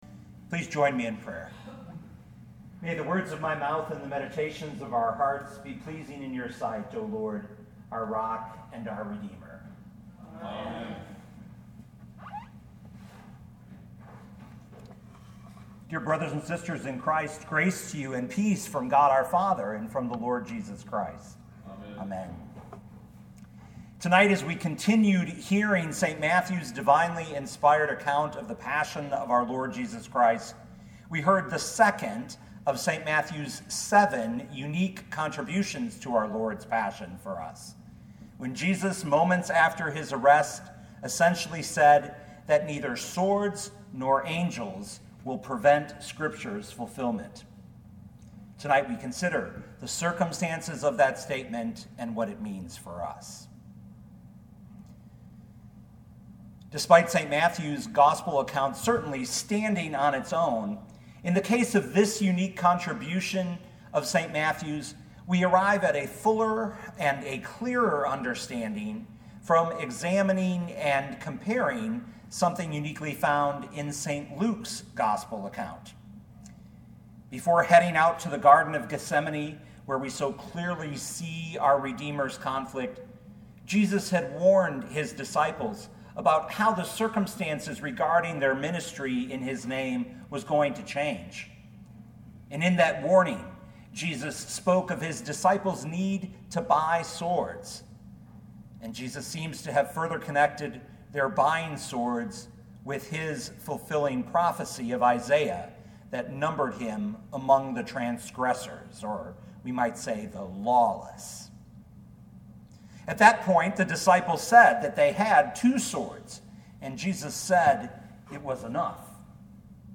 2020 Matthew 26:52b-54 Listen to the sermon with the player below, or, download the audio.